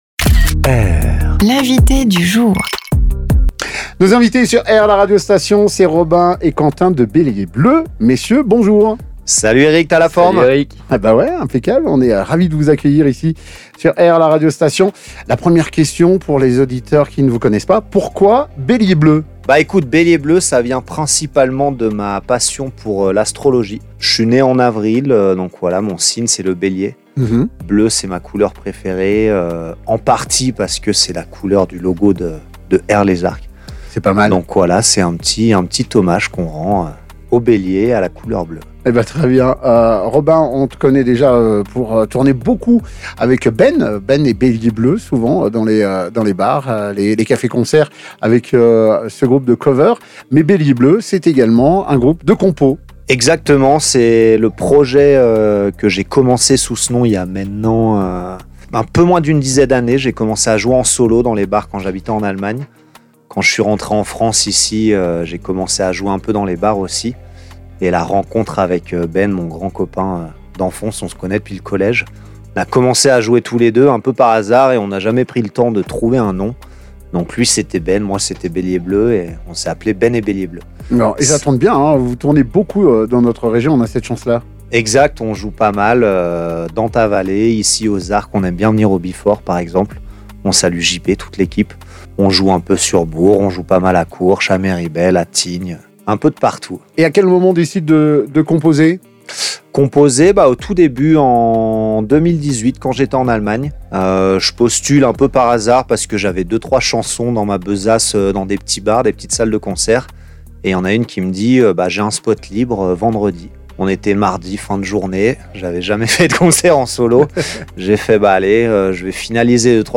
VT-ITW-BELIER-BLEU-ET-LIVE.mp3